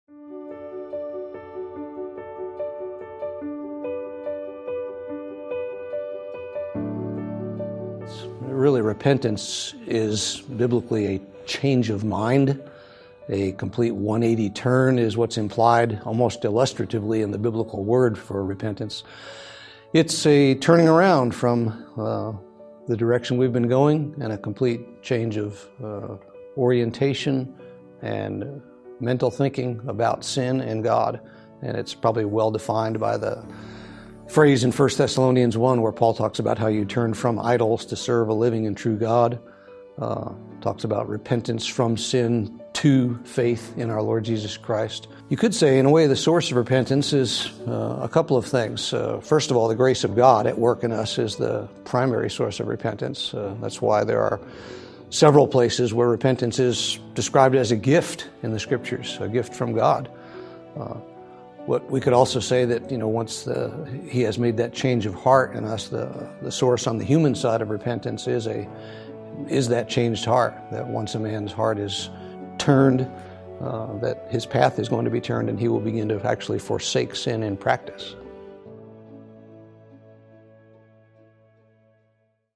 Speaker